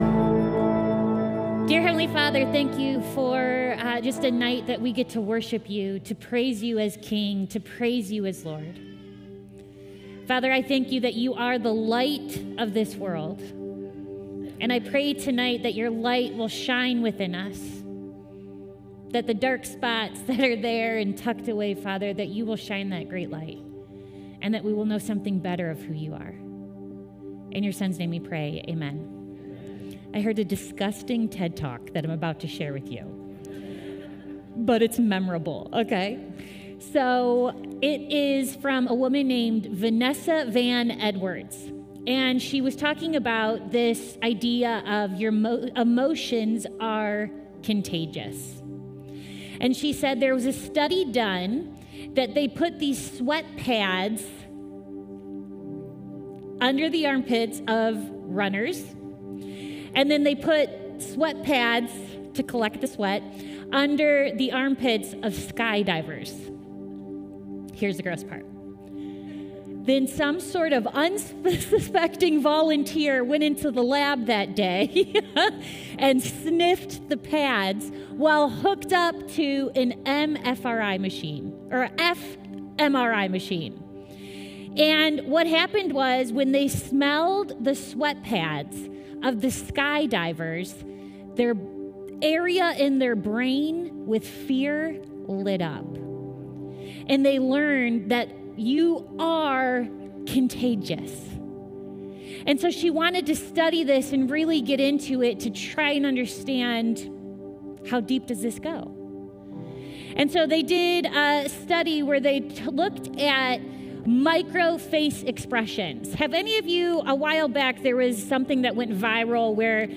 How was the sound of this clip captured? CC Midweek Christmas Worship Night